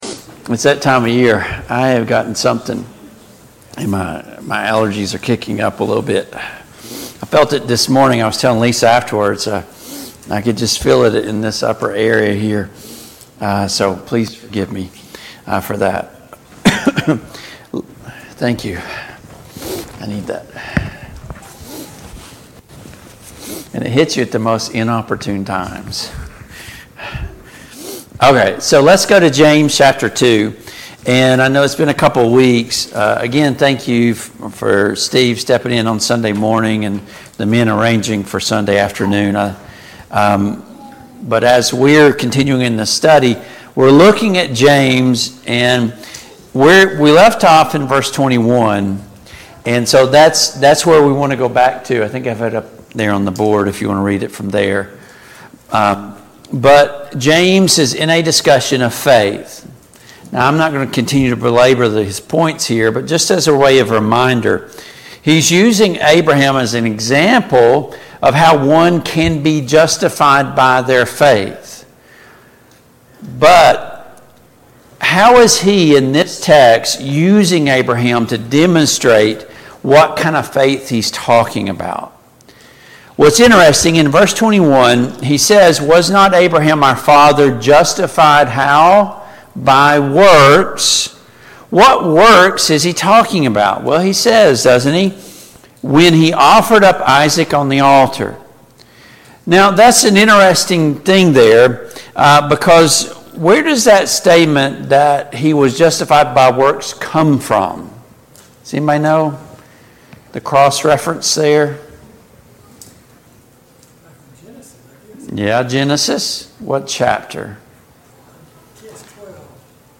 Study of James and 1 Peter and 2 Peter Passage: James 2:18-26 Service Type: Family Bible Hour « Why does a Gracious God still punish us for our sin?